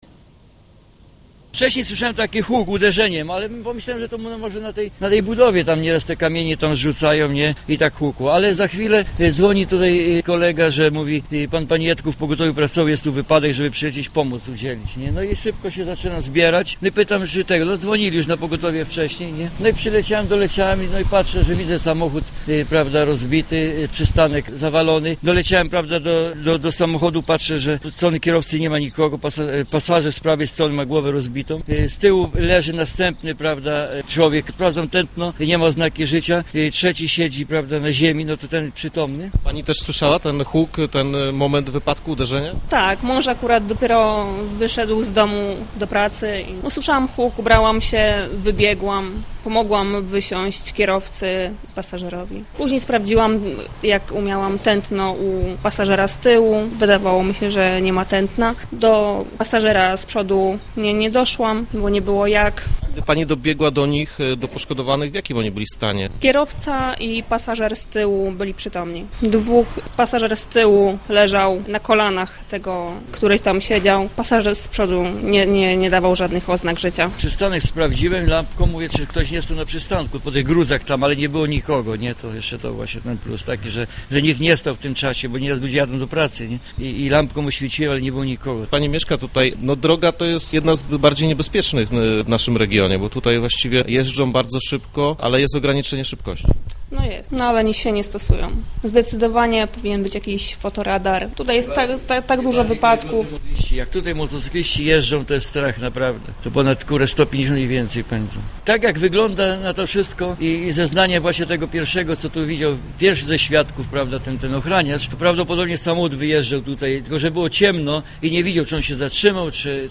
Na miejscu rozmawialiśmy z mieszkańcami Miłosnej.